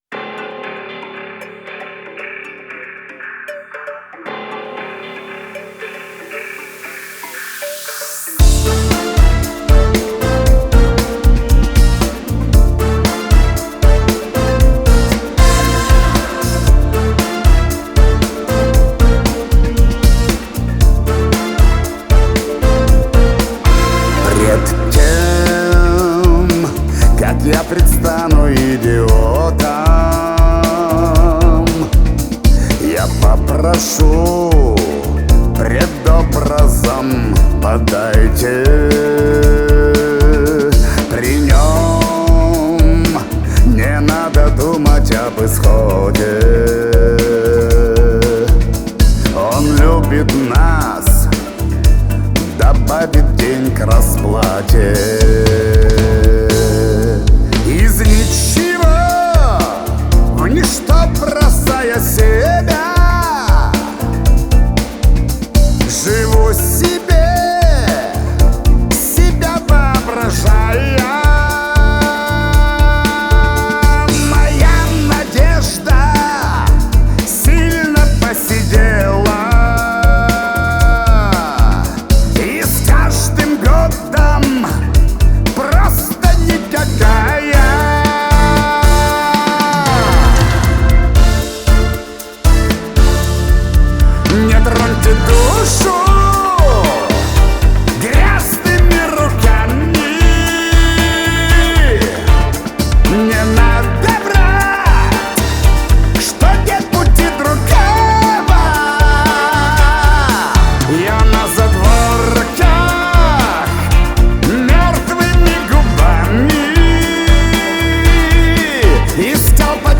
Трек размещён в разделе Русские песни / Эстрада / 2022.